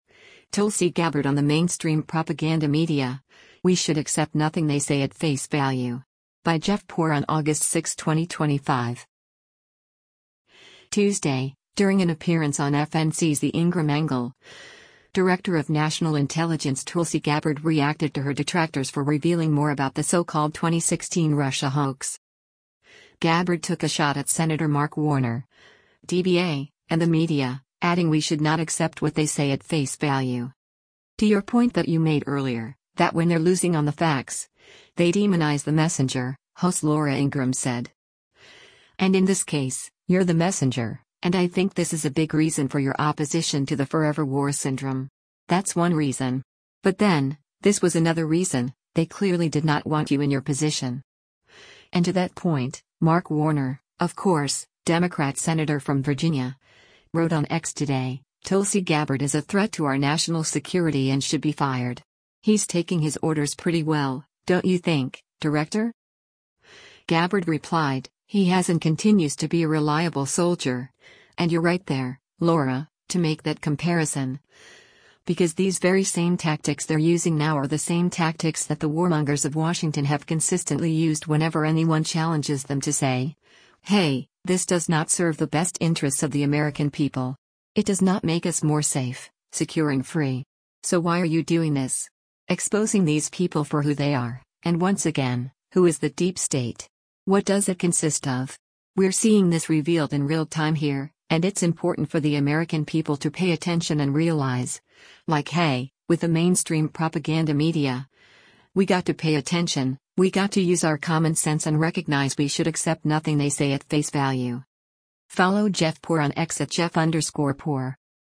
Tuesday, during an appearance on FNC’s “The Ingraham Angle,” Director of National Intelligence Tulsi Gabbard reacted to her detractors for revealing more about the so-called 2016 Russia hoax.